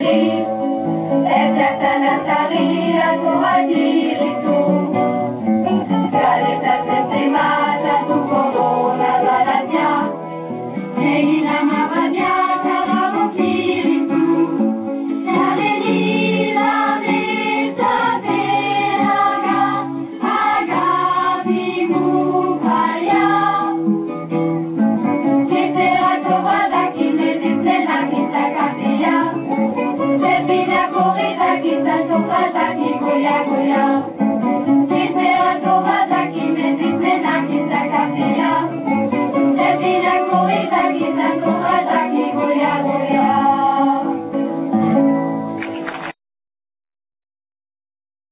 Συνεργασία νεανικής ορχήστρας  κιθάρων από το Αίγιον
με ελληνικές μελωδίες να τραγουδιώνται στα ελληνικά